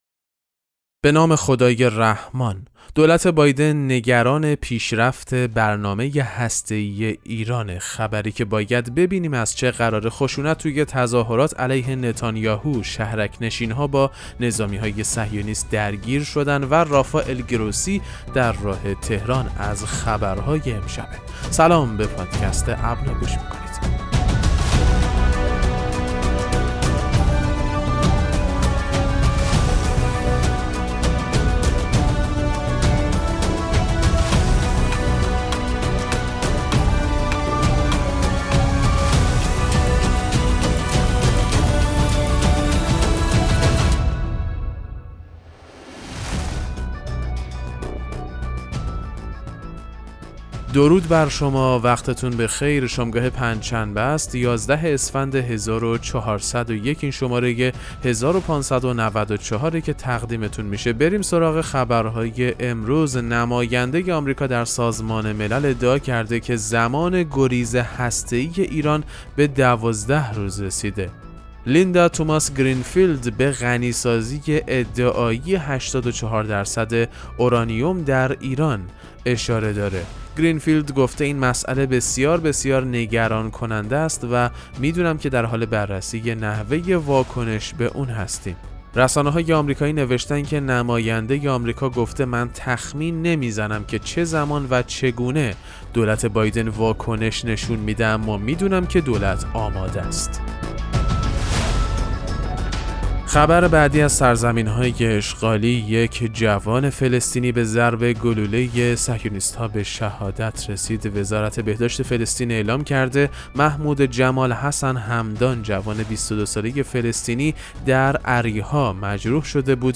پادکست مهم‌ترین اخبار ابنا فارسی ــ 11 اسفند 1401